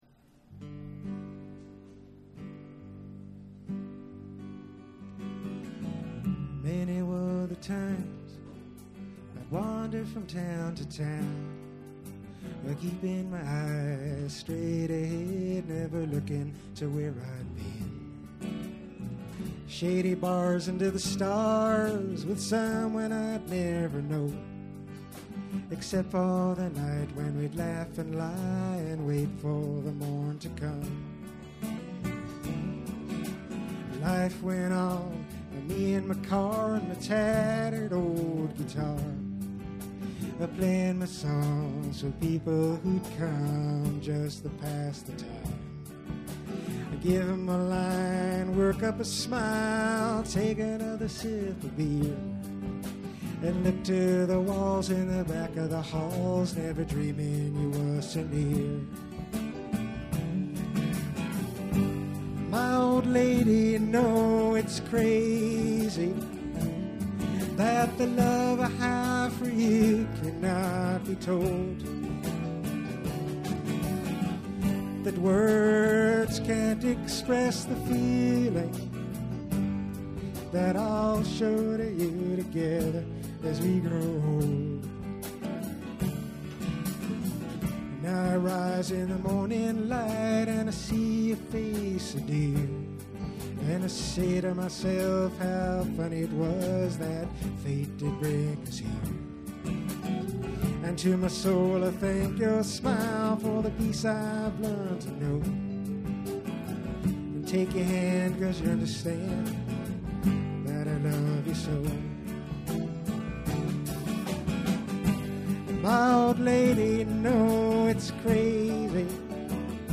Vintage Live & Rehearsal Recordings